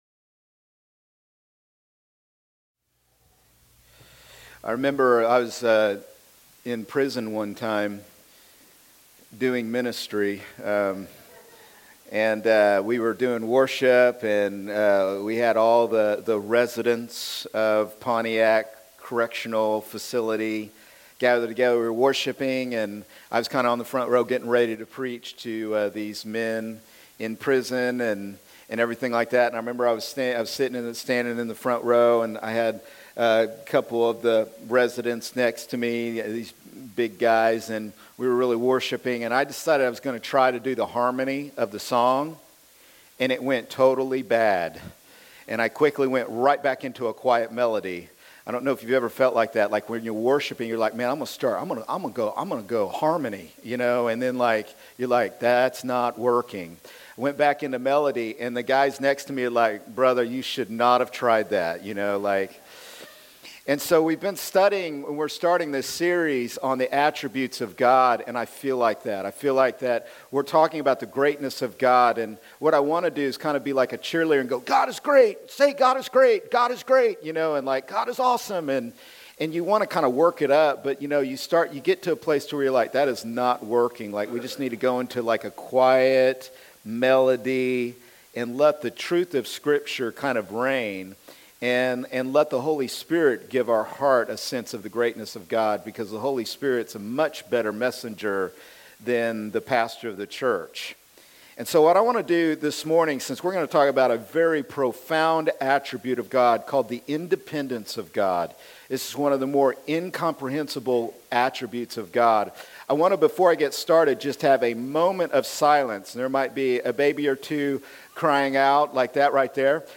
Weekly messages from the worship center of CrossPoint Church in East Peoria, Illinois.